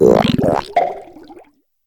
Cri de Pêchaminus dans Pokémon HOME.